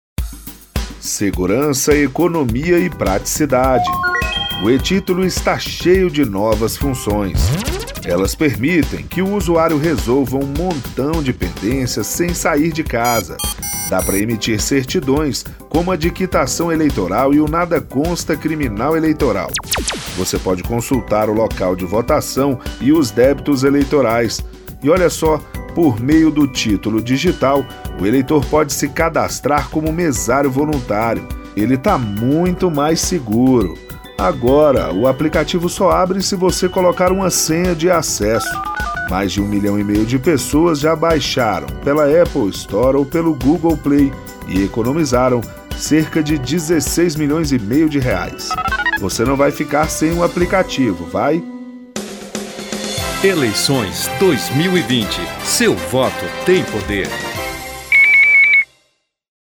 SPOT RÁDIO E-TITULO - TSE
spot-radio-e-titulo-tse.mp3